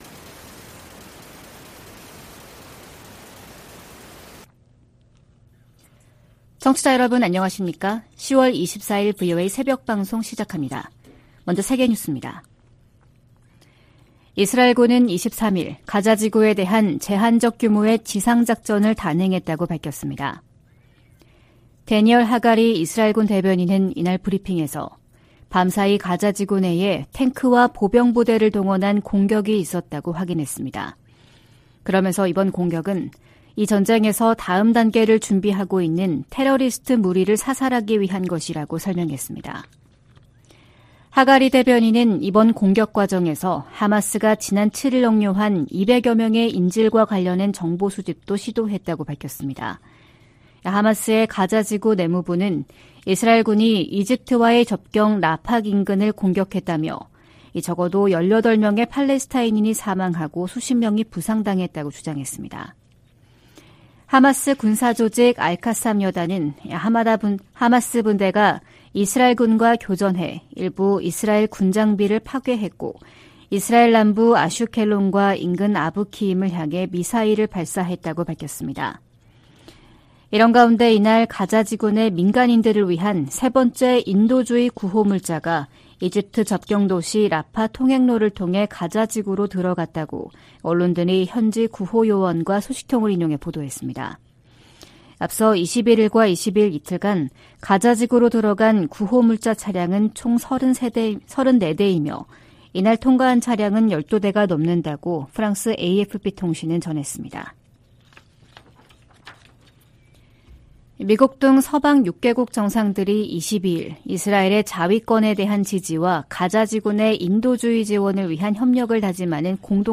VOA 한국어 '출발 뉴스 쇼', 2023년 10월 24일 방송입니다. 미국 백악관은 북한에서 군사 장비를 조달하려는 러시아의 시도를 계속 식별하고 폭로할 것이라고 강조했습니다. 미국 정부가 북한과 러시아의 무기 거래 현장으로 지목한 라진항에 또다시 컨테이너 더미가 자리했습니다.